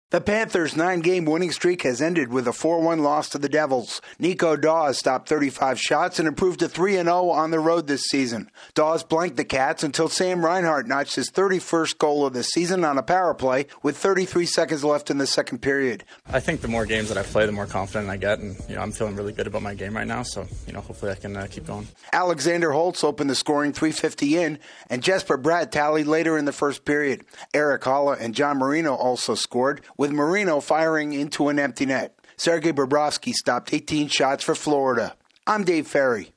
The Texans advance in the AFC playoffs. Correspondent